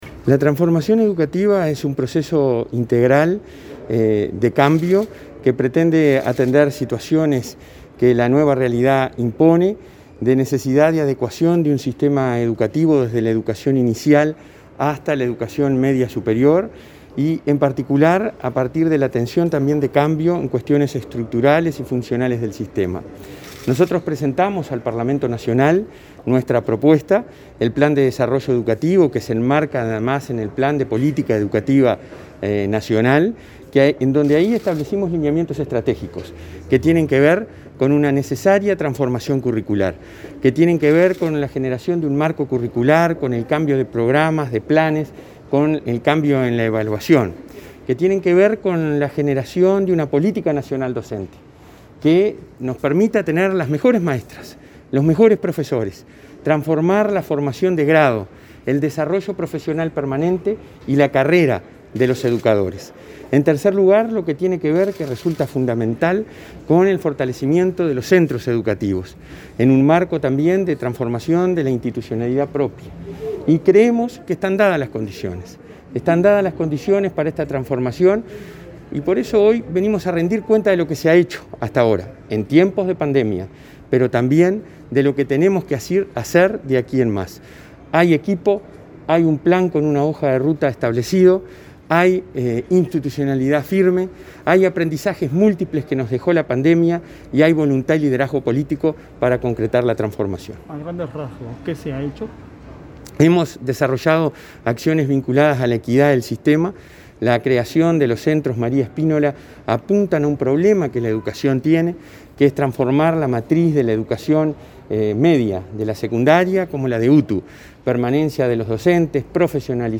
Declaraciones a la prensa del presidente de ANEP, Robert Silva